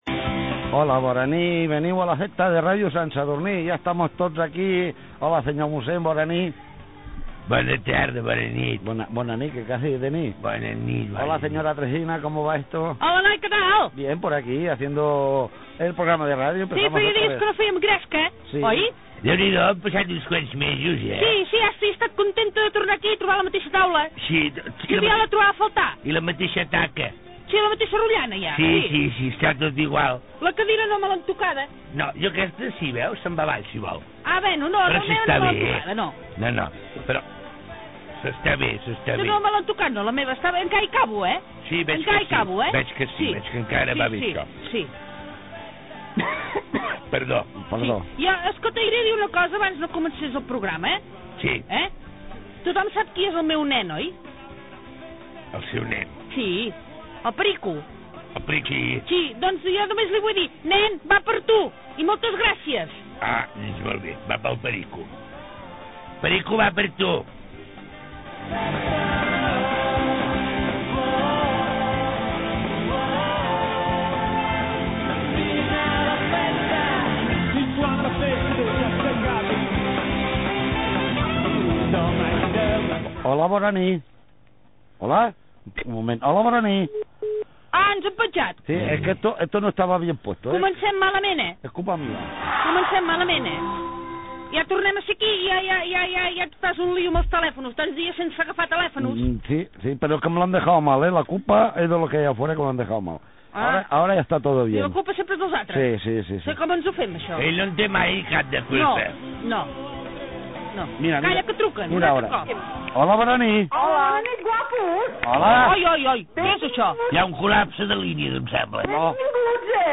Diàleg entre els tres personatges (la senyora Tresina, el señor Pepe Antequera i el mossèn Arnau), dues trucades telefòniques
FM